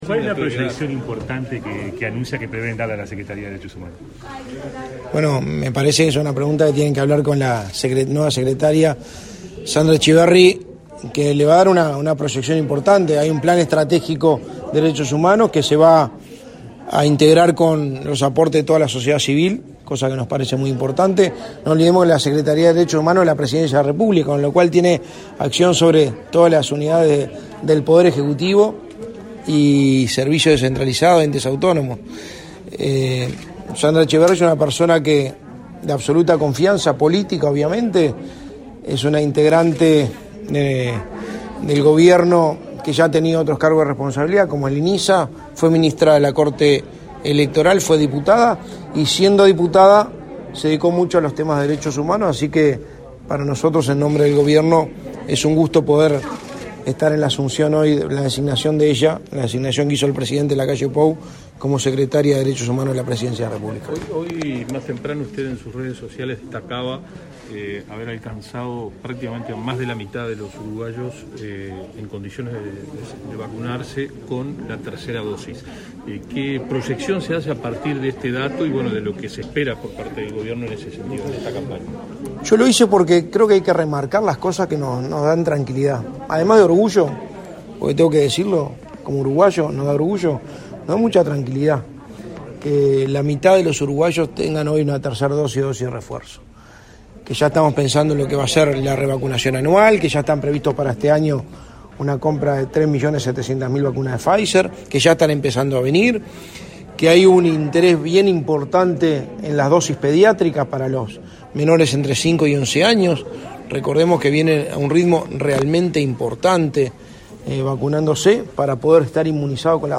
Declaraciones a la prensa del secretario de la Presidencia, Álvaro Delgado
Tras participar en la asunción de la directora de la Secretaría de Derechos Humanos, este 26 de enero, el secretario de la Presidencia, Álvaro Delgado